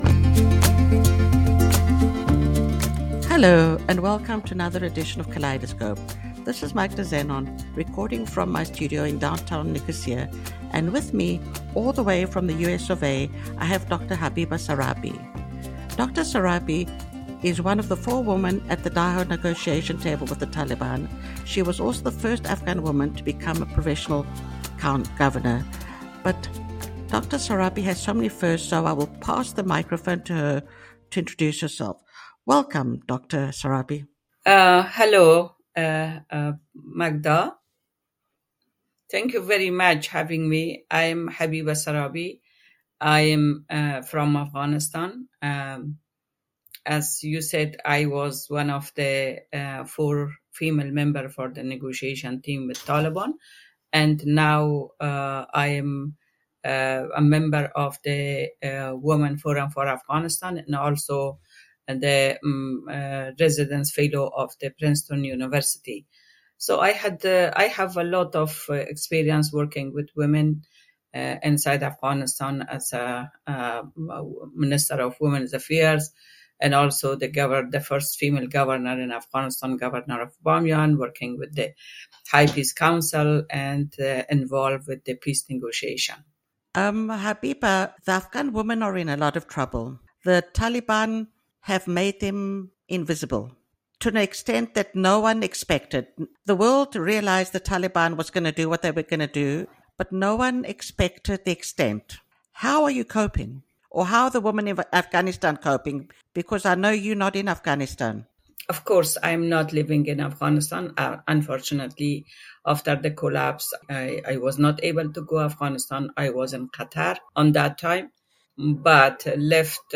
Dr. Habiba Sarābi, the first Afghan woman to become a provincial governor and 1 of the 4 women at the negotiation table with the Taliban in Doha this year, speaks on kaleidHERscope about the life of the women in Afghanistan, the mental health of all Afghans and the rise in suicides and about how we